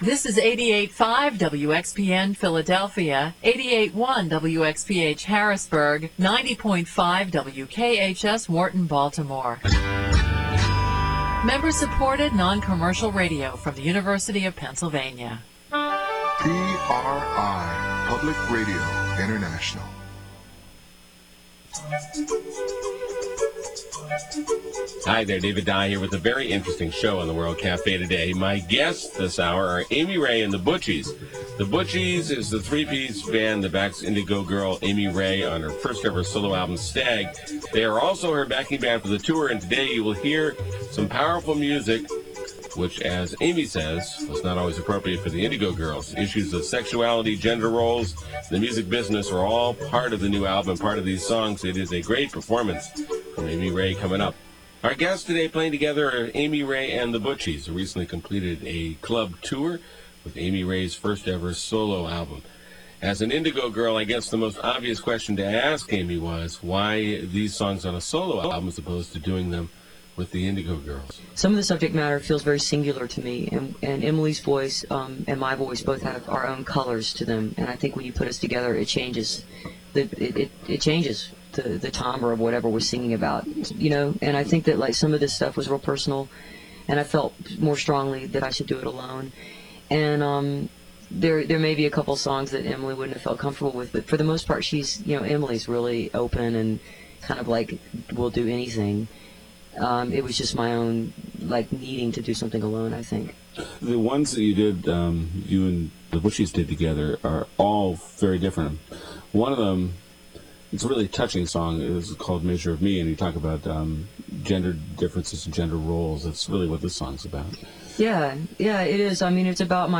01. interview (2:50)